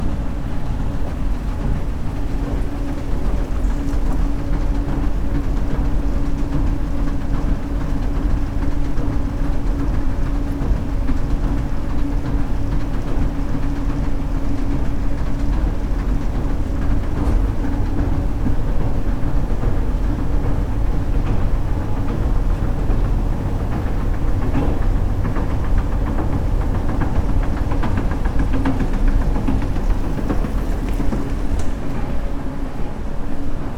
エスカレーター２乗降
escalator2.mp3